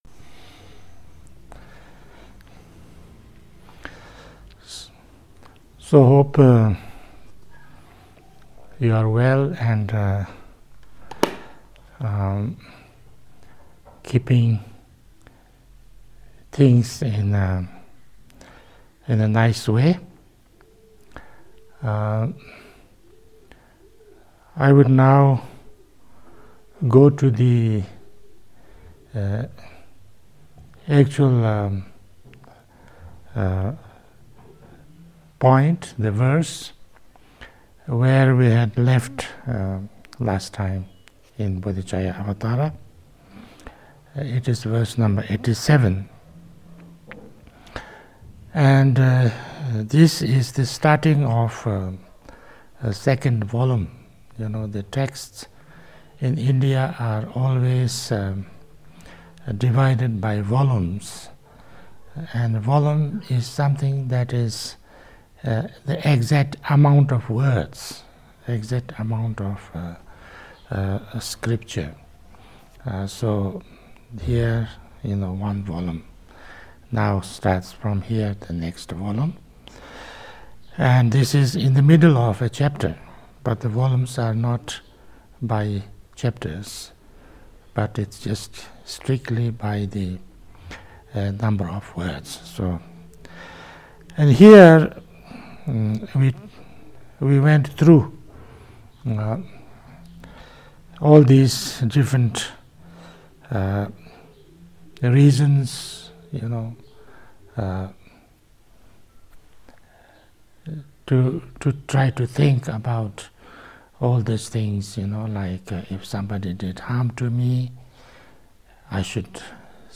Ringu Tulku Rinpoche Teachings on Bodhicharyavatara Session 24 Chapter 6: Patience Stanzas 87-89